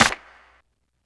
Clap
Original creative-commons licensed sounds for DJ's and music producers, recorded with high quality studio microphones.
Good Clap Sample D# Key 1.wav
large-room-clap-single-hit-d-sharp-key-5-QT7.wav